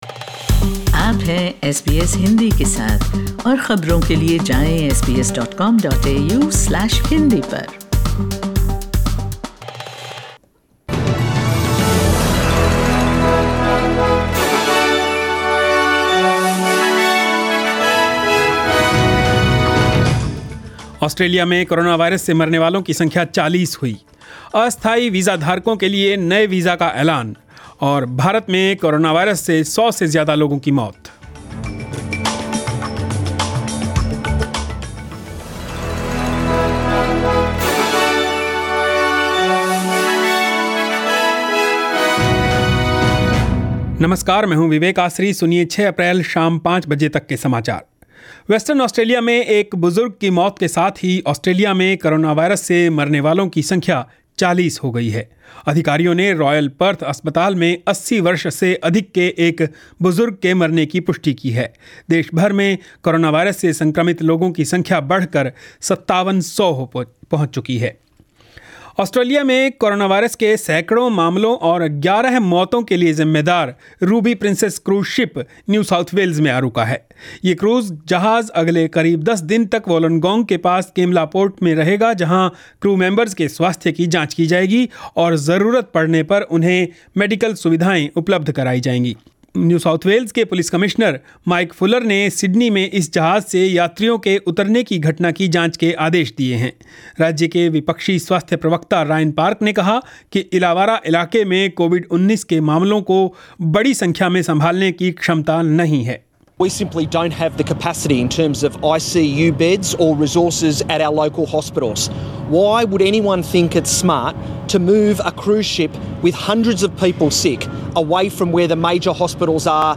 News in Hindi 6 April 2020